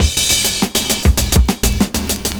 100CYMB02.wav